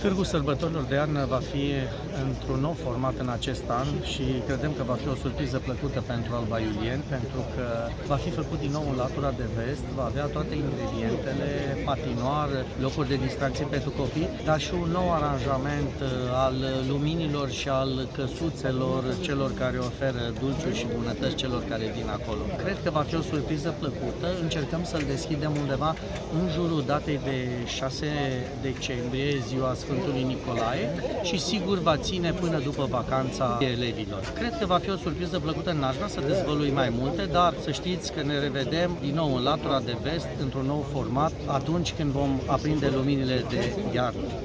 În ceea ce privește târgul de Crăciun care se ține în fiecare an în municipiul Alba Iulia, primarul Gabriel Pleșa a venit cu o serie de informații, care cu siguranță îi vor bucura pe mulți dintre oamenii care de abia așteaptă debutul sezonului rece și al sărbătorilor sale.